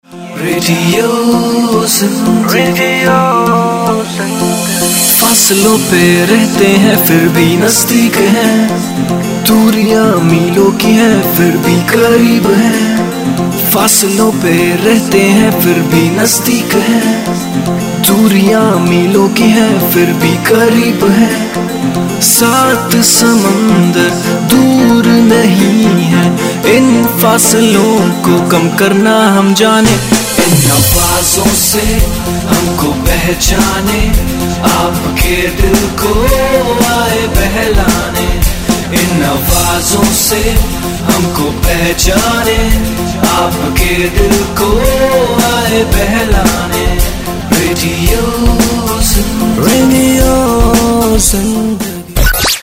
Stingers, Bumpers & Station Jingles